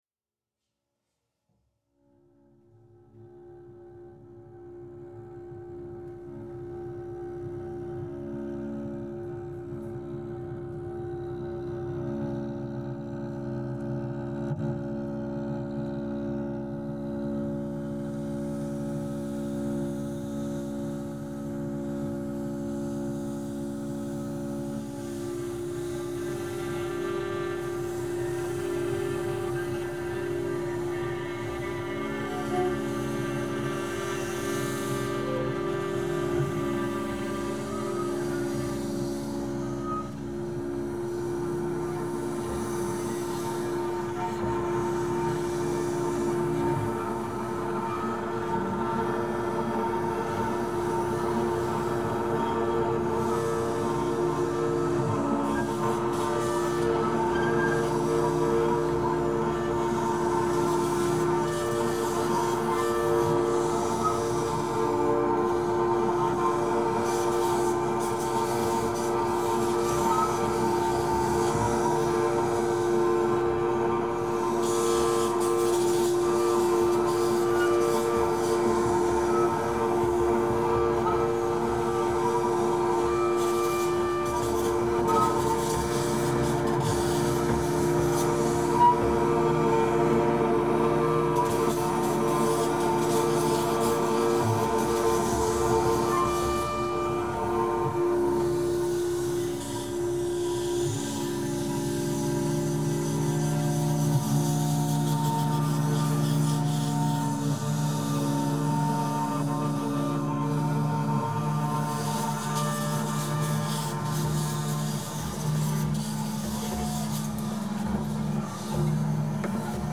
viola
cello
double bass
harmonium, objects
Genre: Avantgarde.